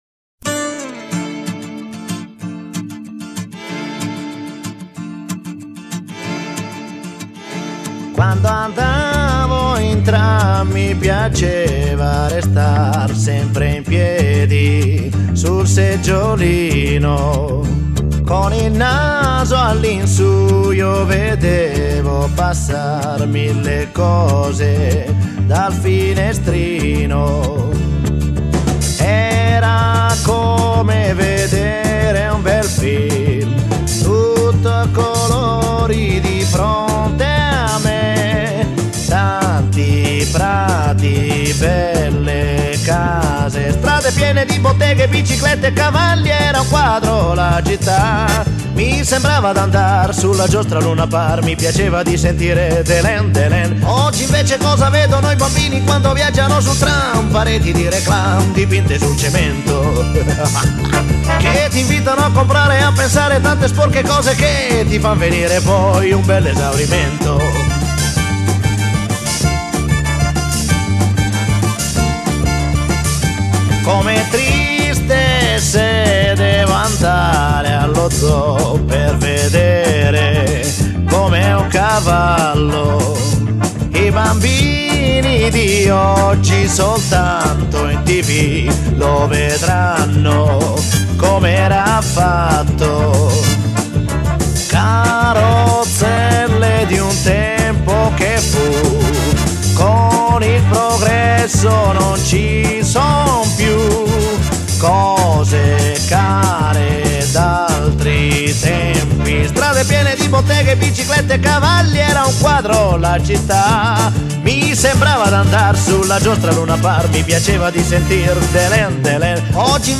Небольшой микс для Вас из популярных песен.